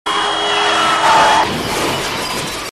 • LOUD CAR CRASH.wav
LOUD_CAR_CRASH_w3K.wav